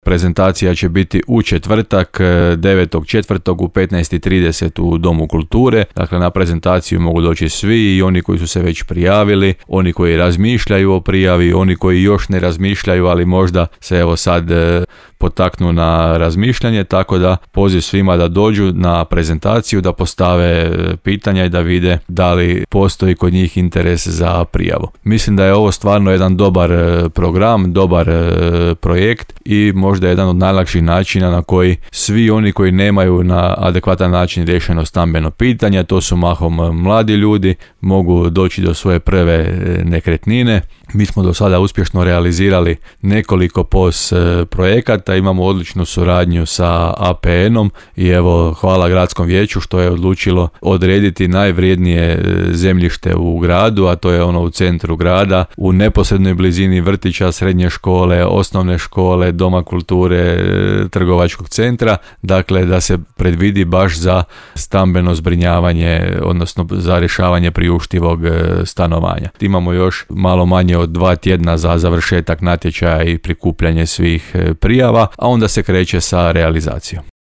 -najavio je gradonačelnik Grada Đurđevca Hrvoje Janči.